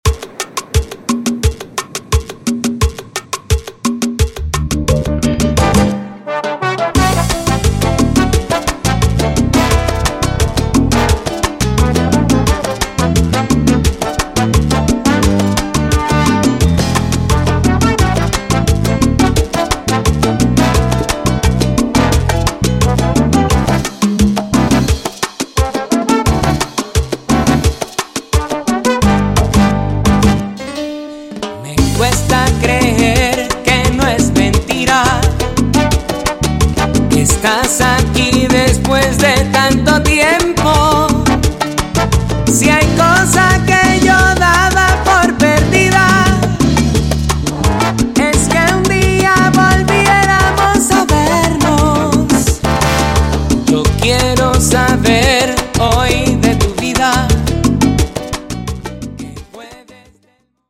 salsa remix